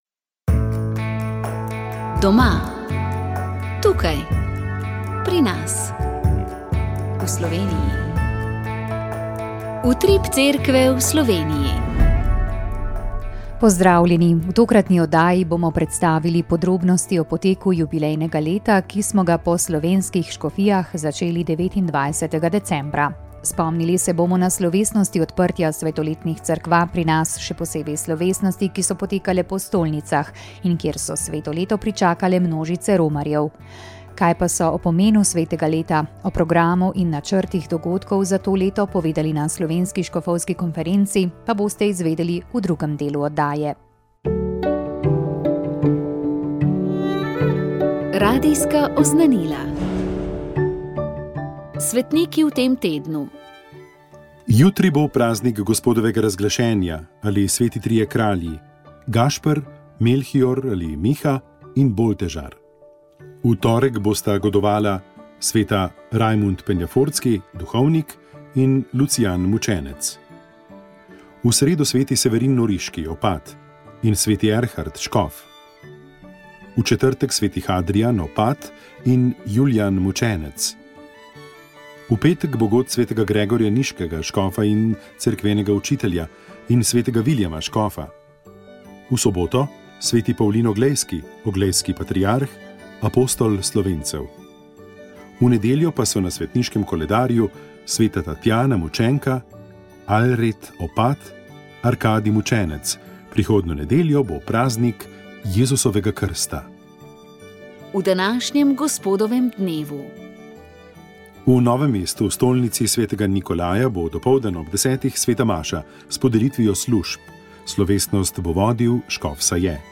Molili so radijski sodelavci.